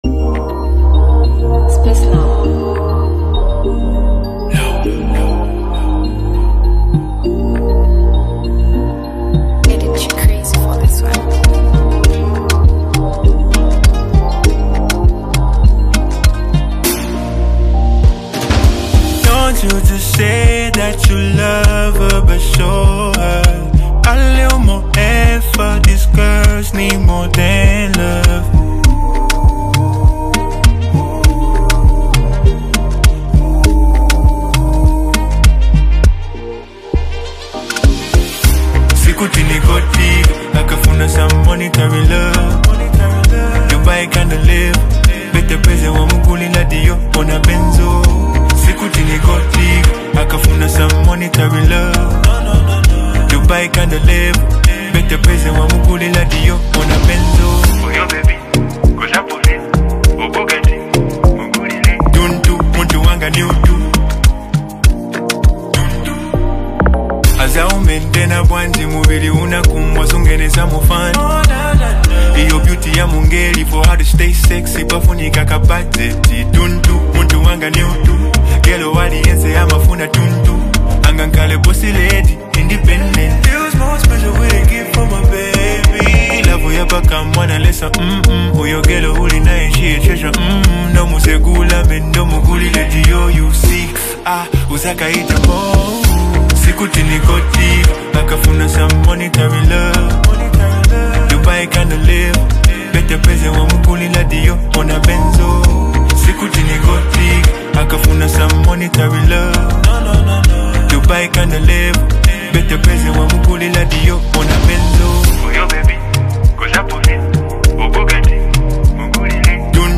delivers a vibrant and engaging sound